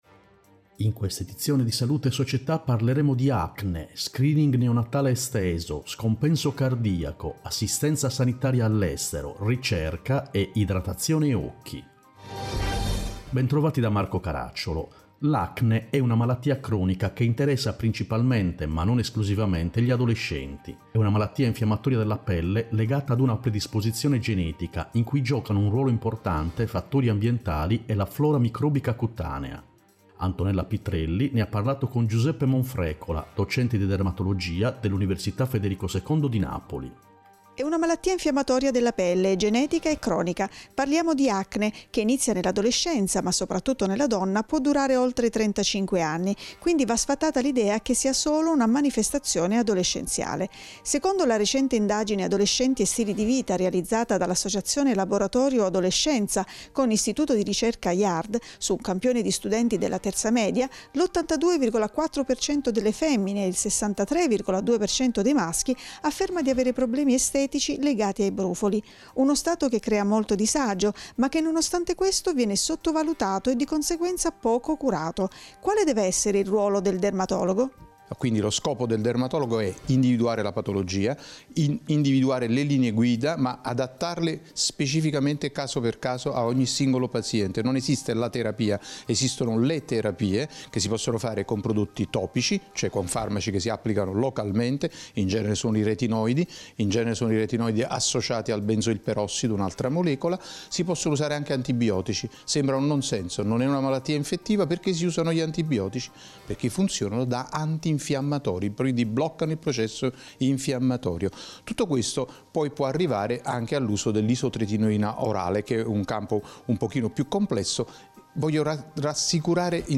In questa edizione: Acne, Malattia cronica SNEinAction, Screening Neonatale Esteso Scompenso cardiaco, Principale malattia vascolare International Sos, Assistenza medico-sanitaria Ricerca, Gilead premia 53 progetti Idratazione e occhi, Come mantenerli in salute Interviste